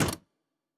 UI Tight 20.wav